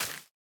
Minecraft Version Minecraft Version 1.21.5 Latest Release | Latest Snapshot 1.21.5 / assets / minecraft / sounds / block / spore_blossom / break5.ogg Compare With Compare With Latest Release | Latest Snapshot